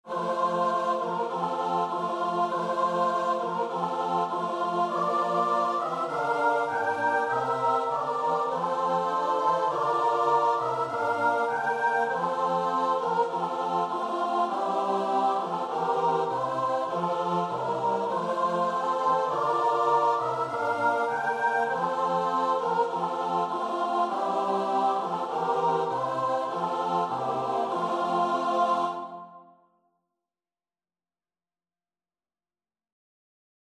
Free Sheet music for Choir (SATB)
SopranoAltoTenorBass
4/4 (View more 4/4 Music)
Classical (View more Classical Choir Music)